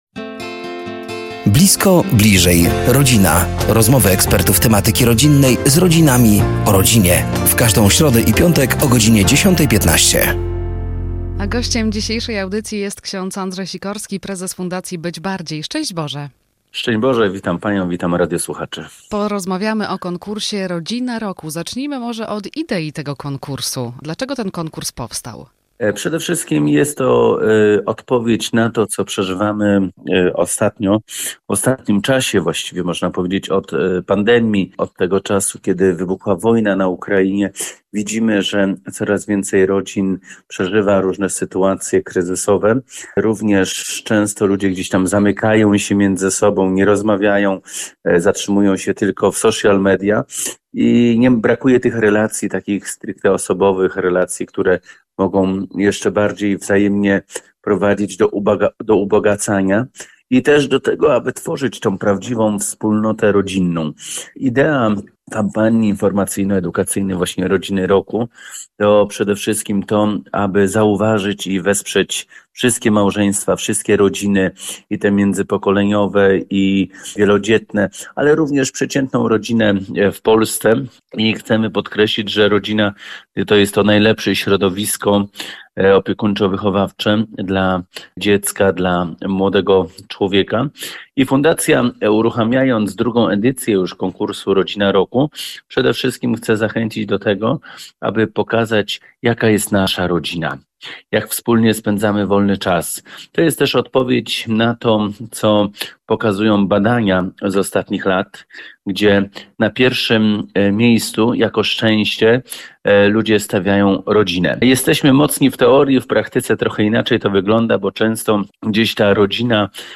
Blisko. Bliżej. Rodzina! To cykl audycji na antenie Radia Nadzieja. Do studia zaproszeni są eksperci w temacie rodziny i rodzicielstwa.